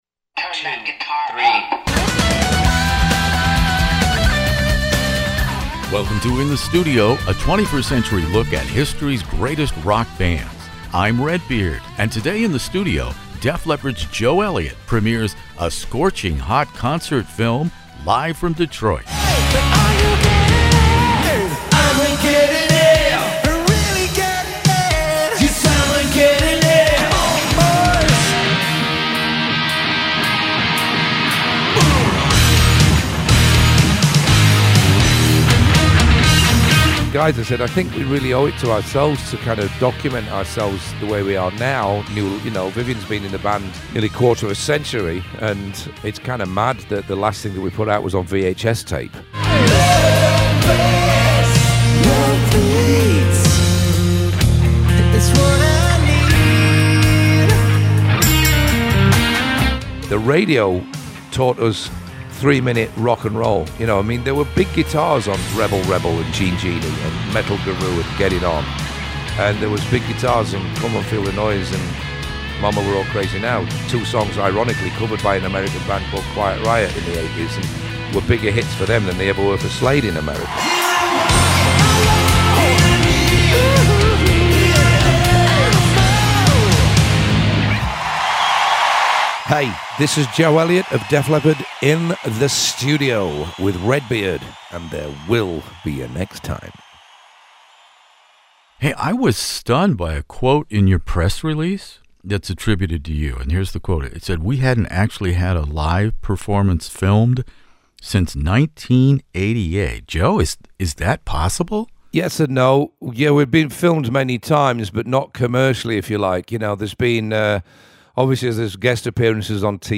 One of the world's largest classic rock interview archives, from ACDC to ZZ Top, by award-winning radio personality Redbeard.
Def Leppard lead singer Joe Elliott joins me In the Studio for a lively conversation covering a wide range of topics, including high-tech isolation and the almost tribal need for people of all ages to physically come together around one unifying purpose.